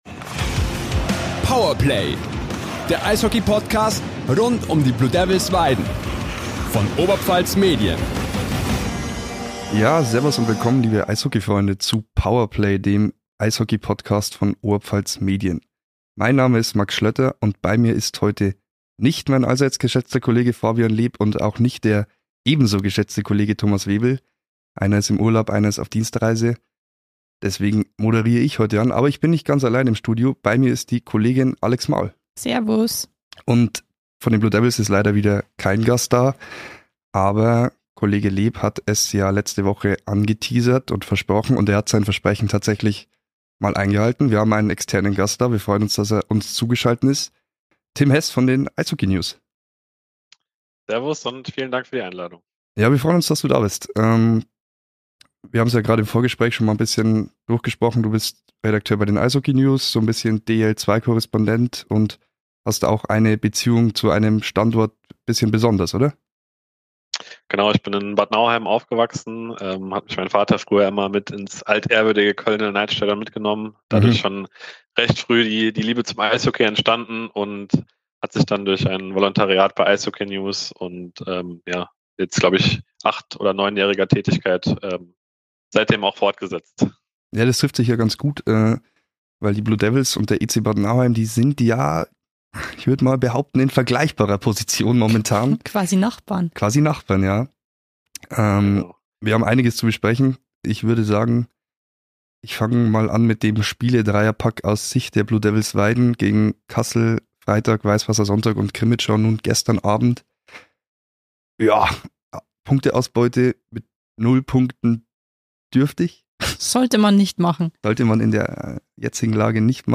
Blue Devils im freien Fall - Expertentalk